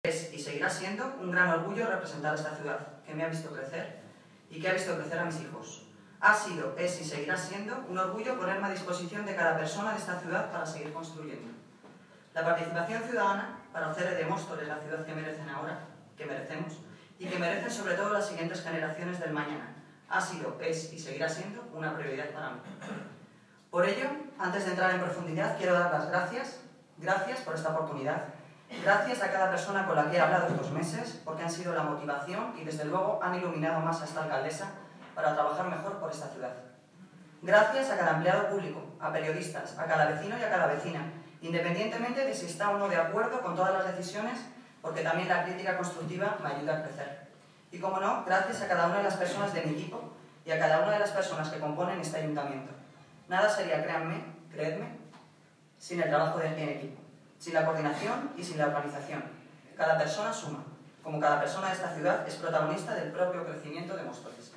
Audio - Noelia Posse (Alcaldesa de Móstoles) Intervención inicial Debate estado del municipio